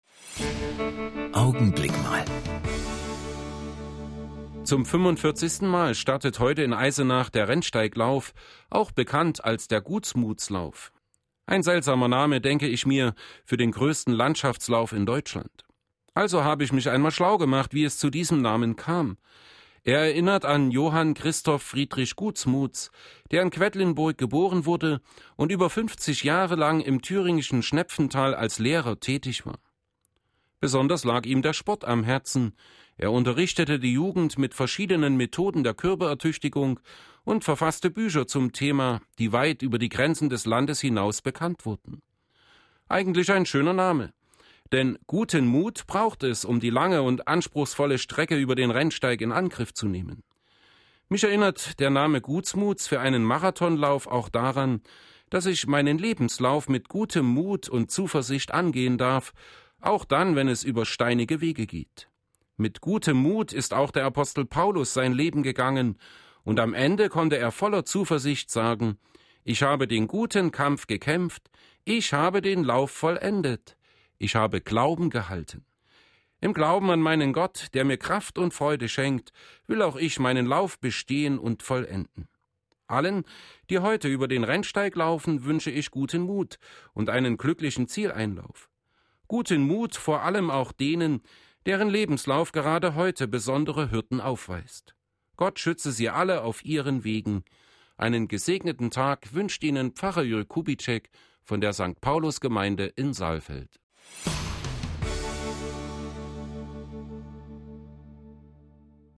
Radioandachten Gottesdienst